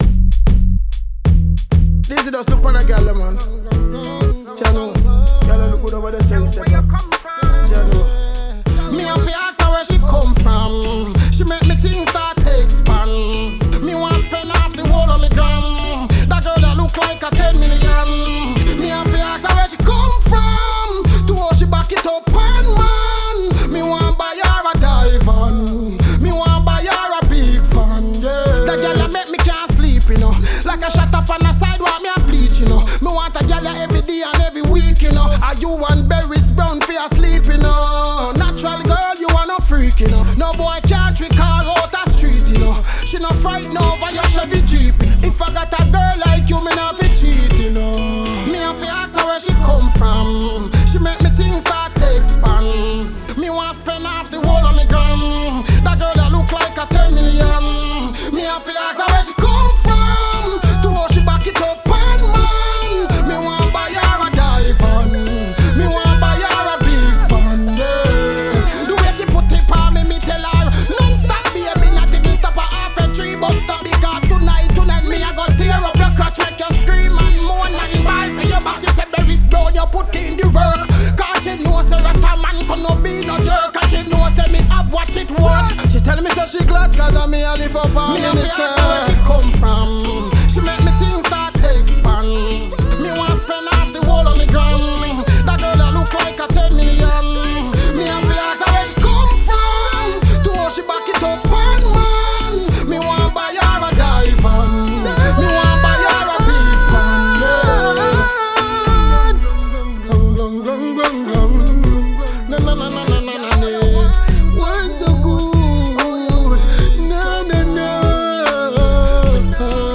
bouncy
reggae , uptemp